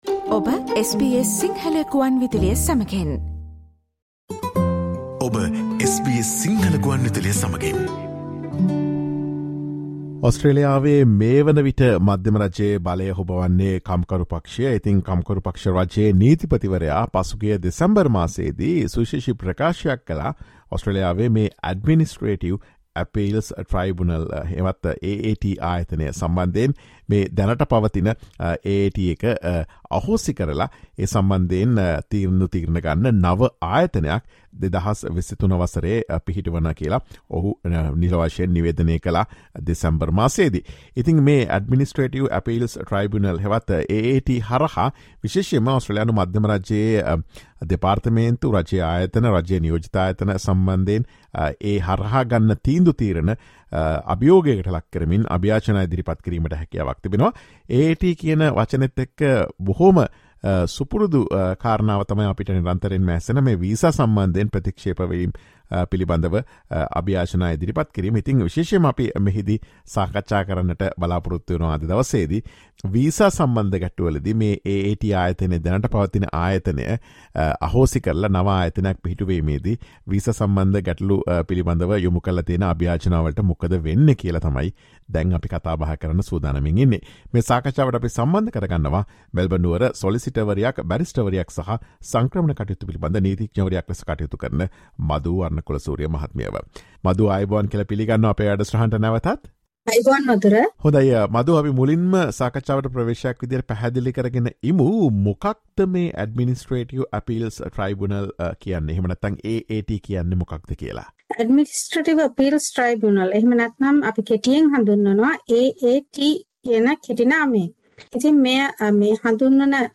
Listen to SBS Sinhala Radio's discussion of the fate of visa appeals due to Australia's decision to abolish the AAT.